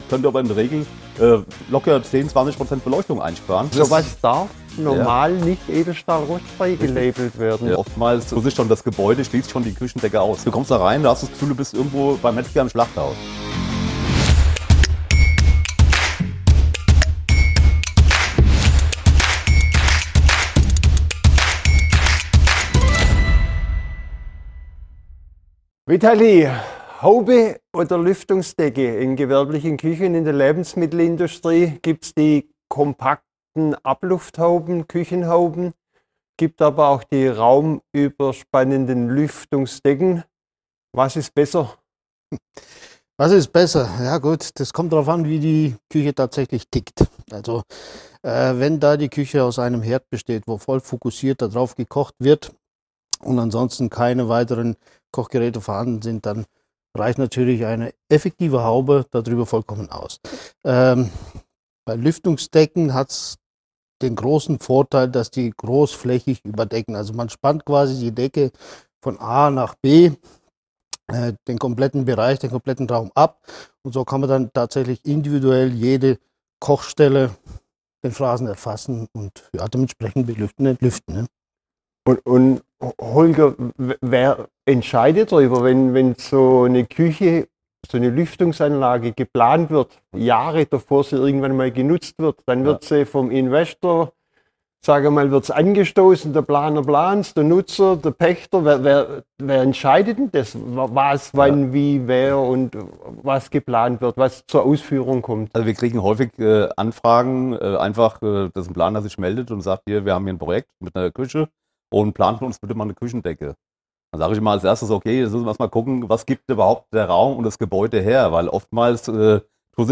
Episode 21 Küchenhaube oder Lüftungsdecke - Expertentalk ~ Luftpost – Der Podcast für industrielle Luftreinigung und Lüftungstechnik Podcast